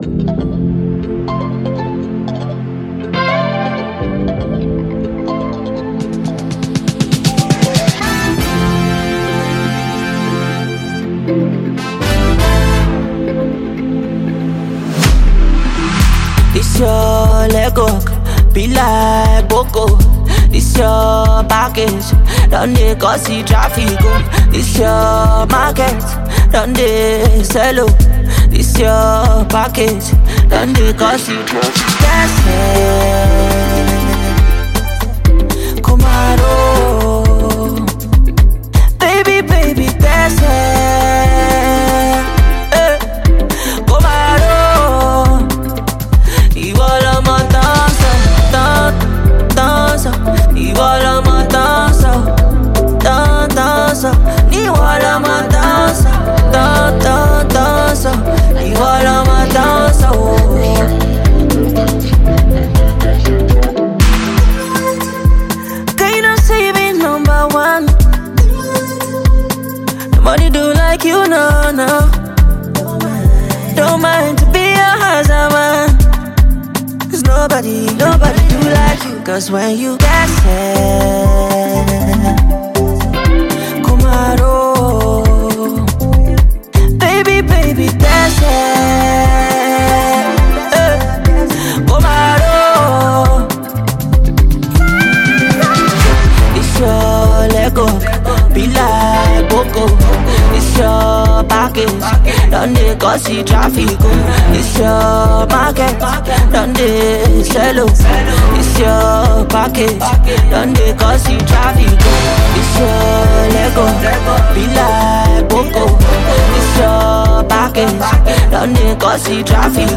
Amapiano influenced record
a feel-good number that is sure to have you moving